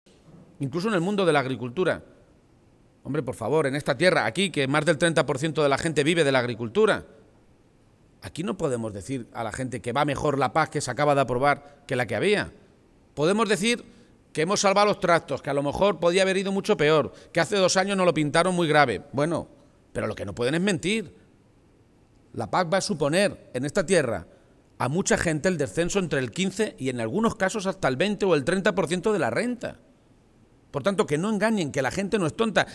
Emiliano García-Page ha realizado estas afirmaciones en Membrilla durante un encuentro con militantes, y simpatizantes del PSOE de Ciudad Real.